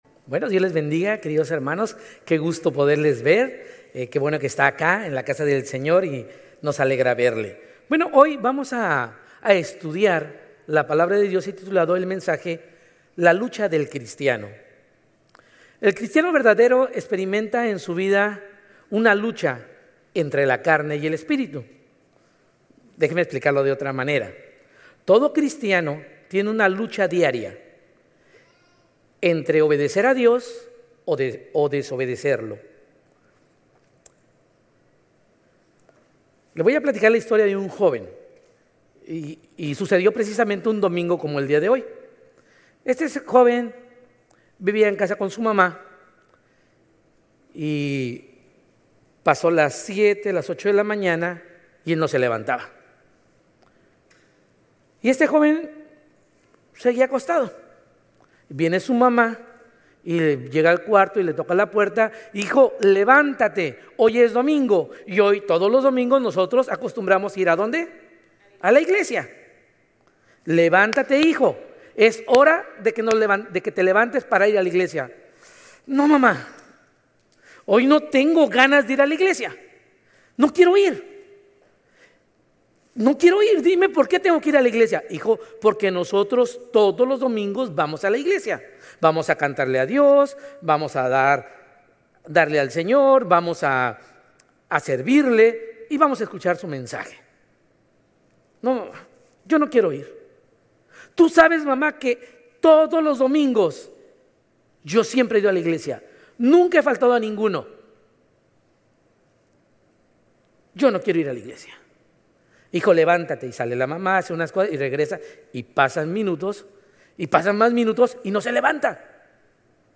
Sermones Grace Español 8_31 Grace Espanol Campus Aug 31 2025 | 00:37:08 1x 00:00 / 00:37:08 Subscribe Share RSS Feed Share Link Embed